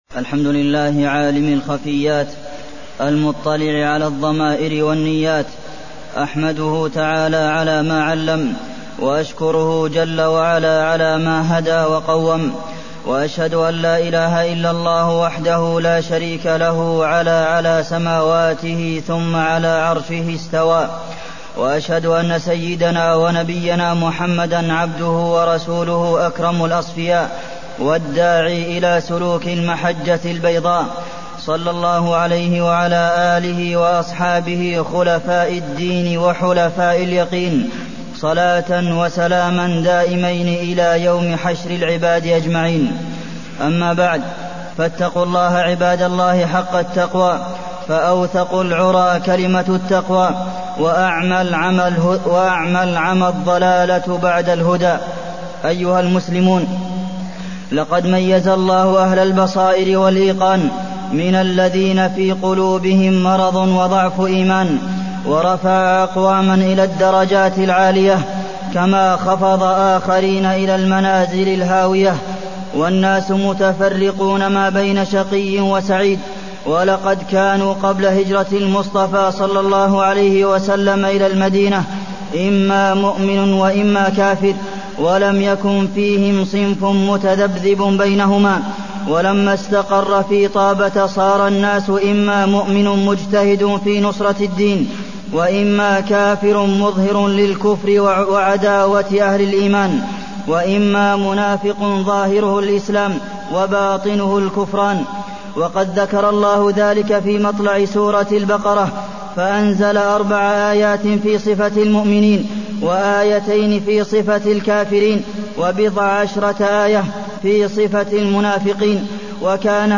تاريخ النشر ١ صفر ١٤٢١ هـ المكان: المسجد النبوي الشيخ: فضيلة الشيخ د. عبدالمحسن بن محمد القاسم فضيلة الشيخ د. عبدالمحسن بن محمد القاسم صفات المنافقين